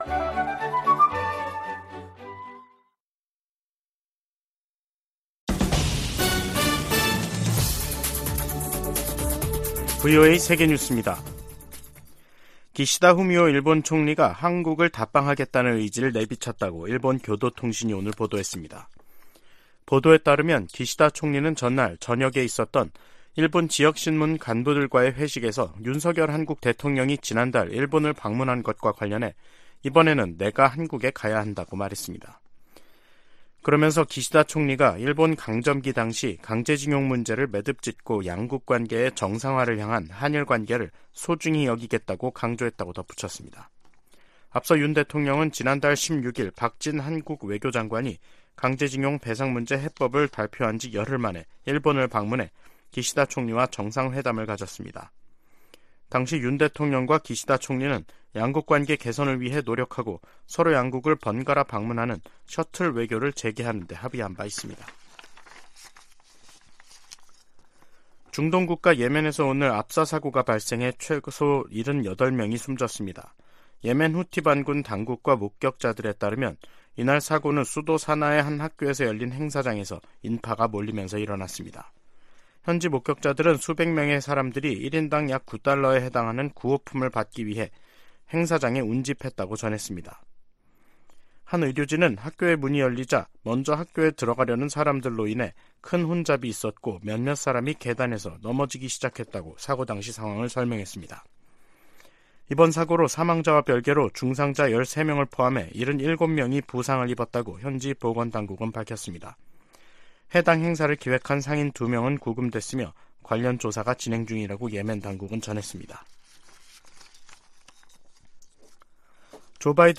VOA 한국어 간판 뉴스 프로그램 '뉴스 투데이', 2023년 4월 20일 2부 방송입니다. 백악관은 윤석열 한국 대통령의 방미 일정을 밝히고, 26일 오벌 오피스에서 조 바이든 대통령과 회담한다고 발표했습니다. 미 국무부는 북한의 불법적인 대량살상무기(WMD) 개발 자금을 계속 차단할 것이라고 밝혔습니다. 윤석열 한국 대통령이 우크라이나에 대한 군사지원 가능성을 시사한 발언에 대해 러시아가 북한에 대한 첨단 무기 제공 등을 거론하며 반발하고 있습니다.